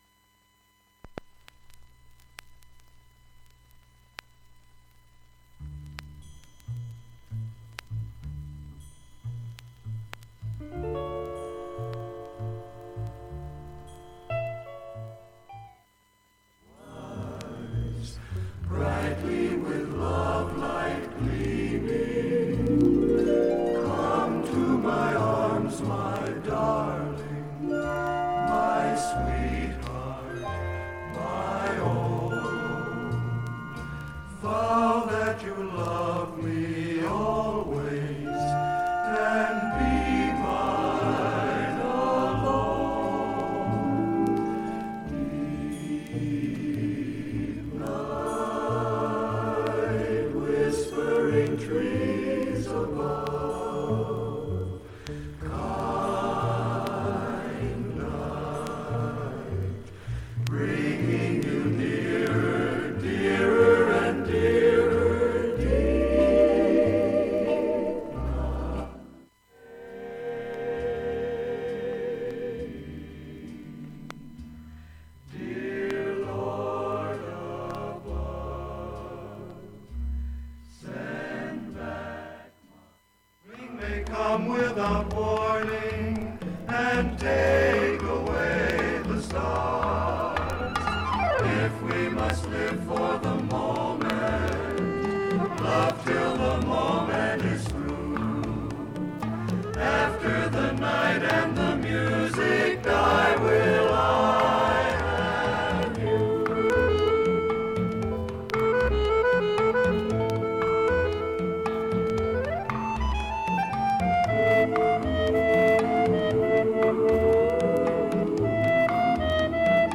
音質きれいです。
A-1始めにかすかなプツが６回出ます。
現物の試聴（上記録音時間3分半）できます。音質目安にどうぞ